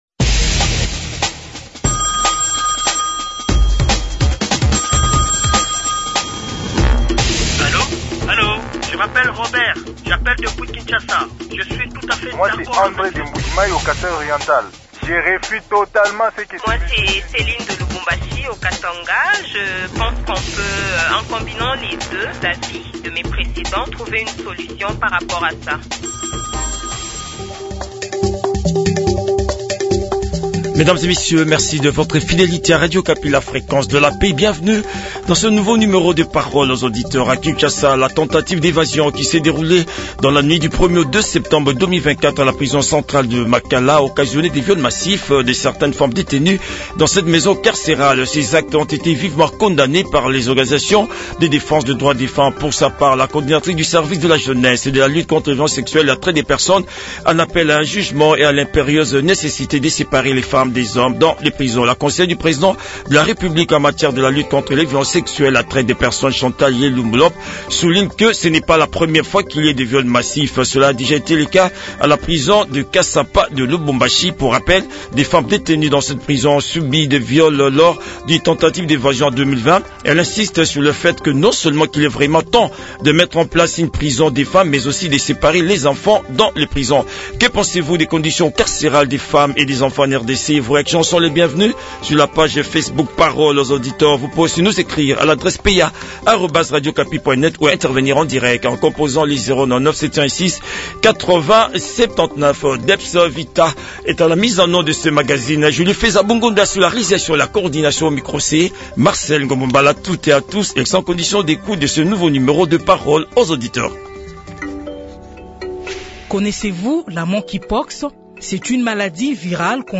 L’échange citoyen s’est déroulé entre les auditeurs et Chantal Yelu